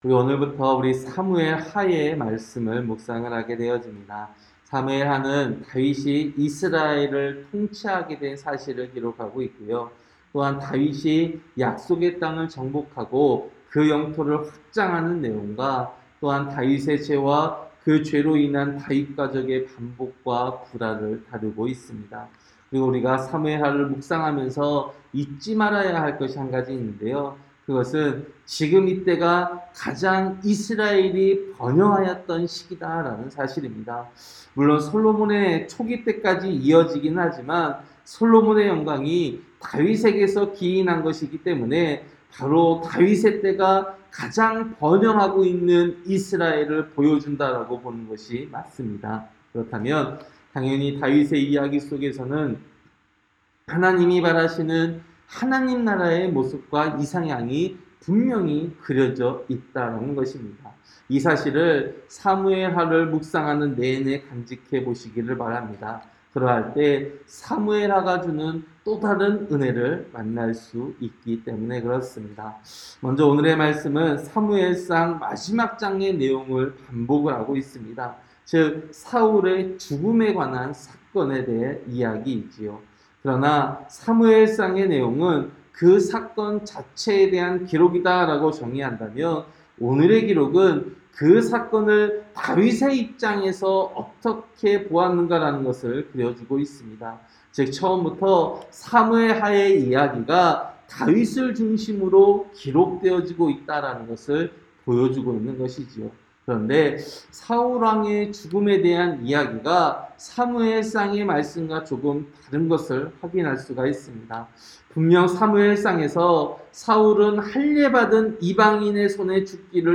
새벽기도-사무엘하 1장